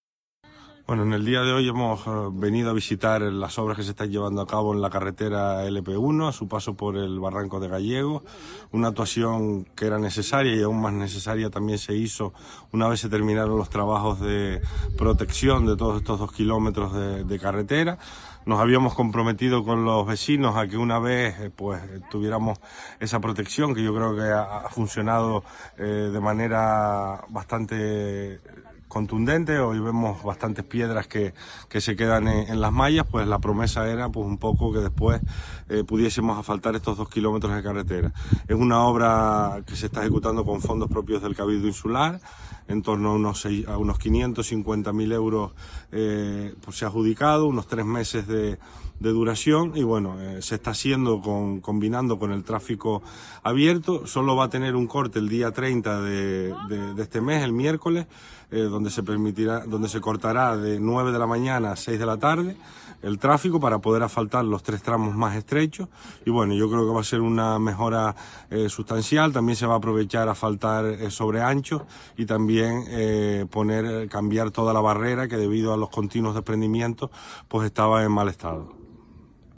Declaraciones audio Borja Perdomo Gallegos.mp3